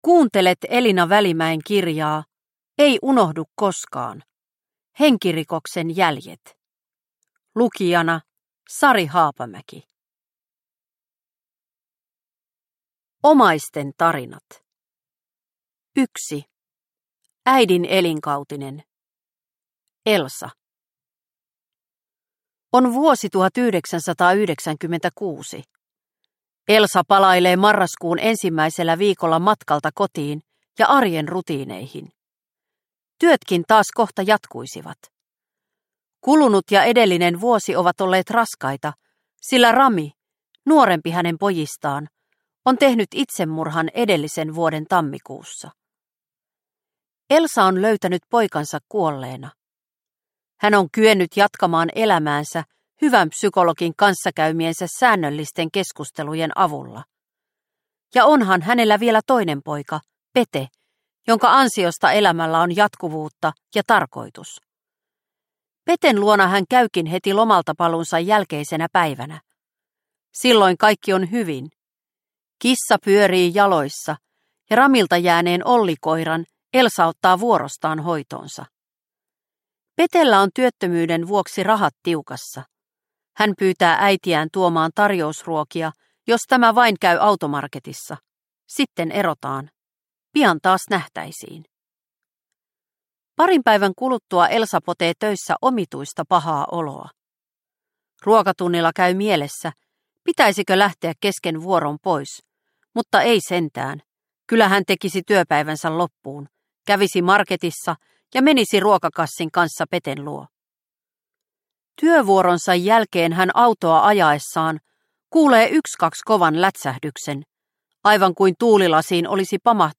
Ei unohdu koskaan – Ljudbok – Laddas ner